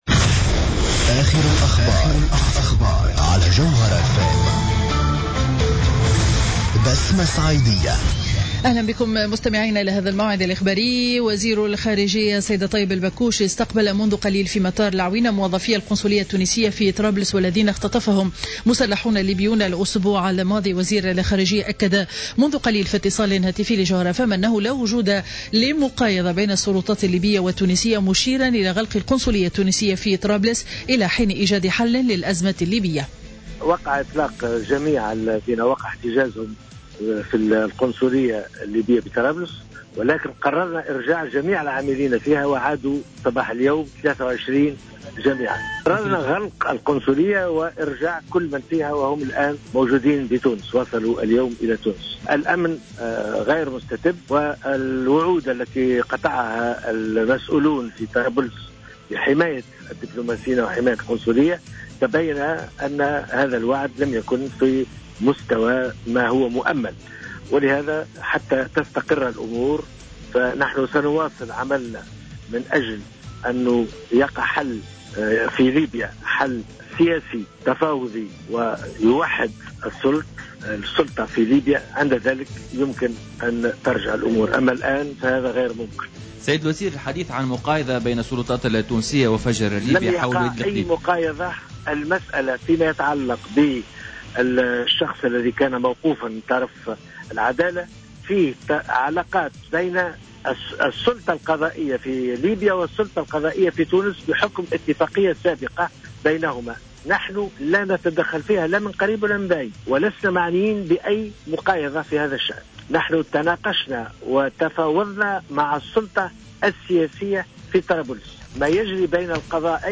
نشرة أخبار منتصف النهار ليوم الجمعة 19 جوان 2015